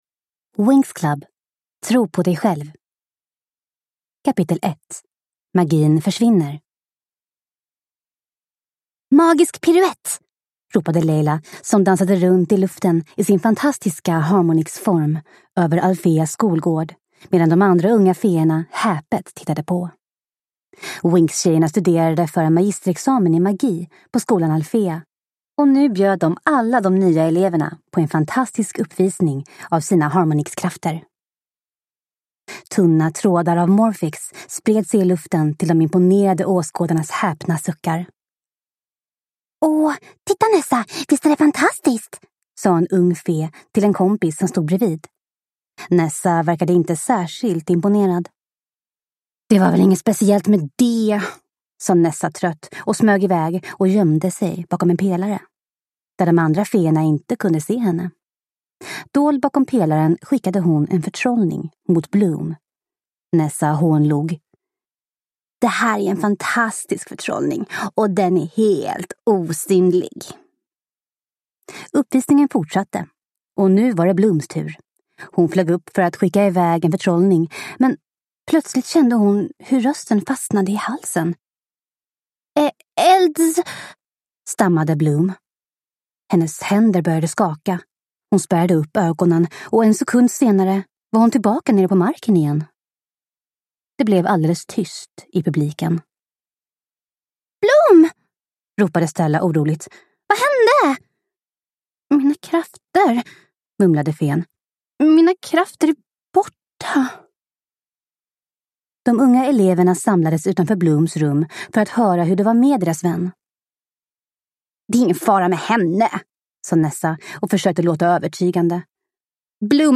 Winx Club: Tro på dig själv! – Ljudbok